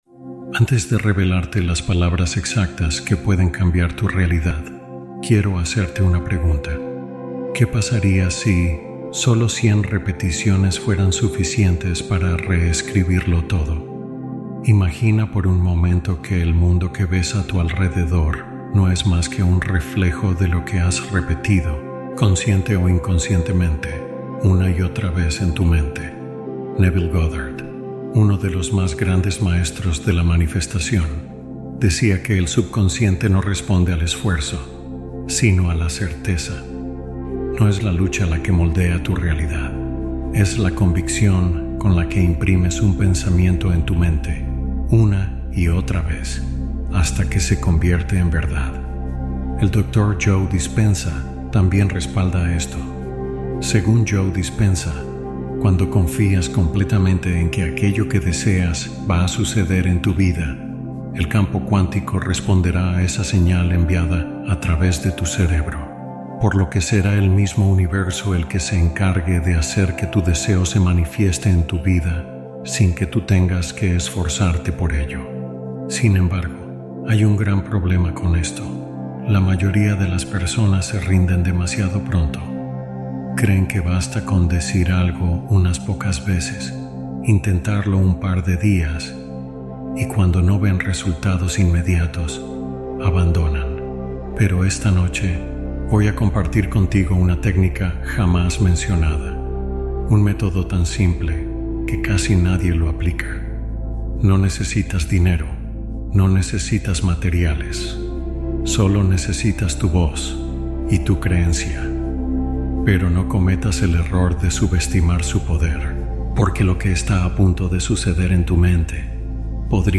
Un mantra sostenido como ejercicio de alineación personal